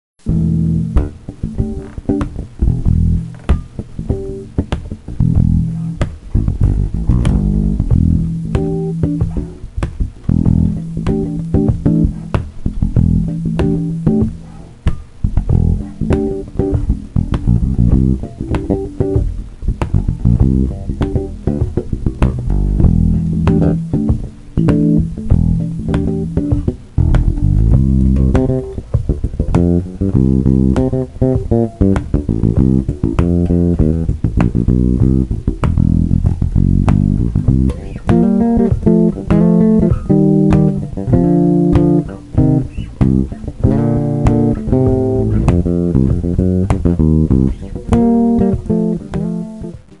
and electric bass